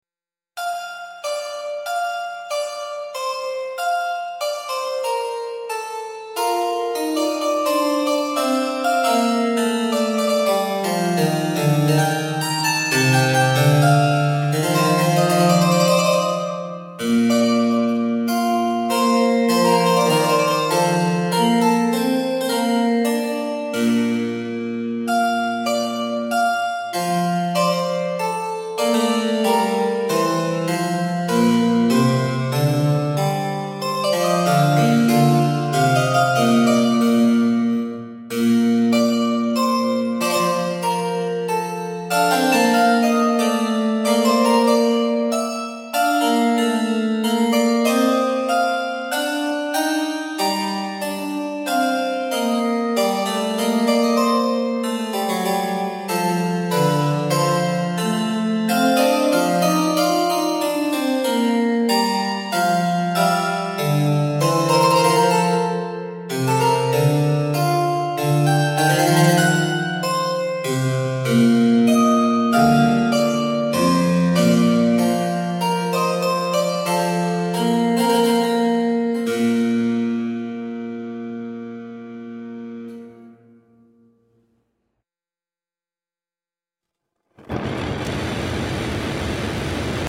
old-world classical elegy with harpsichord and chamber strings